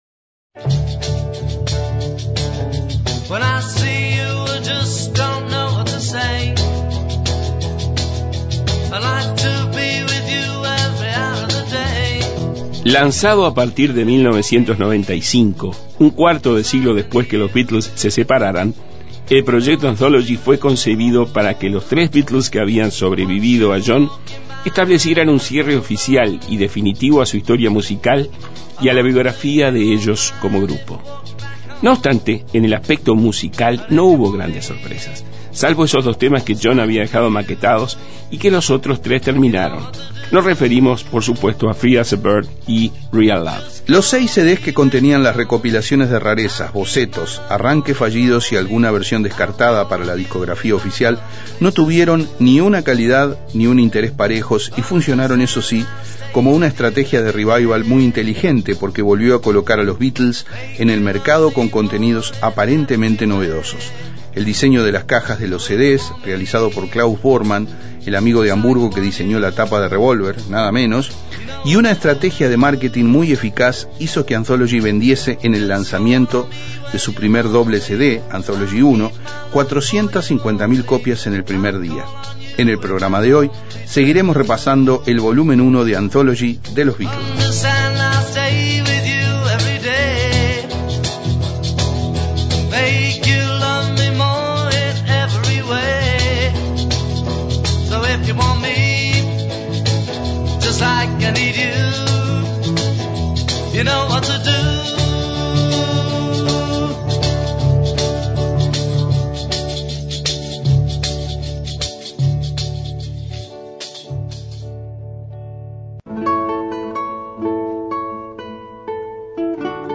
el compilado de temas, maquetas y actuaciones en vivo